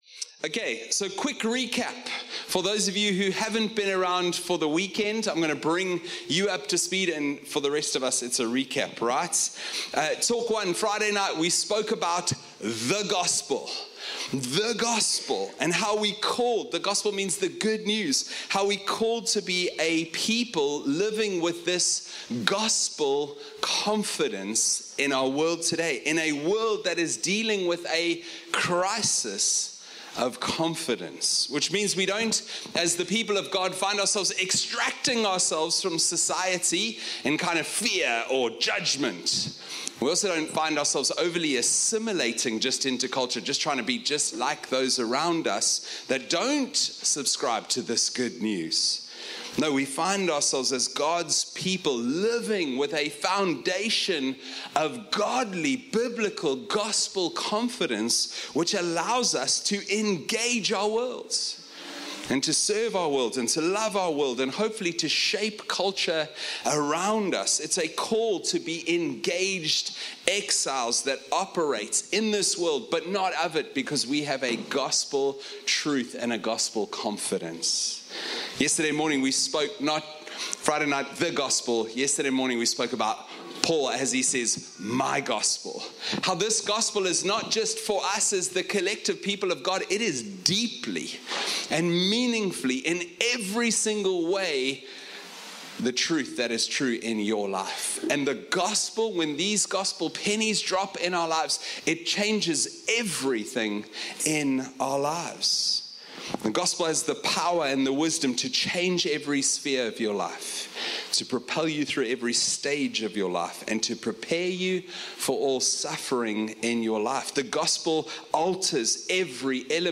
One-Hope-Family-Camp-Sermon-3-2025.mp3